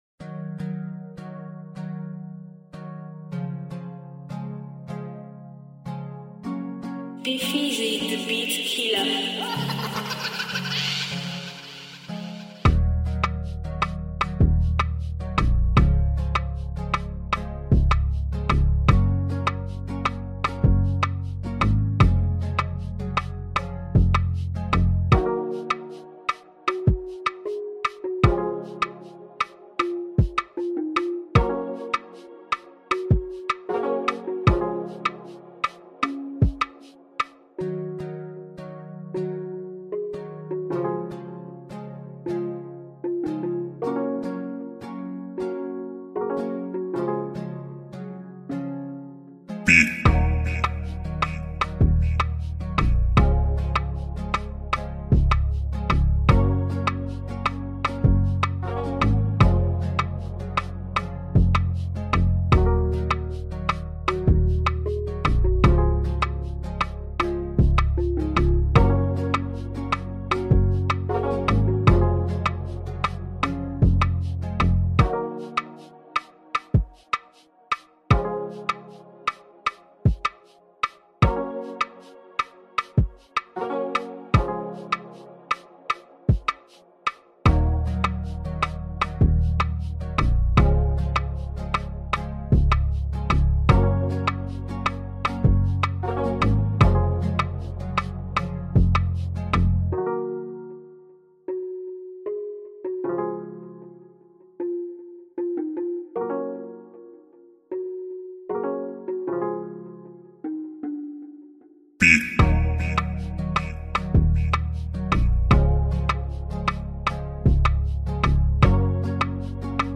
2021-08-31 1 Instrumentals 0
free beat instrumental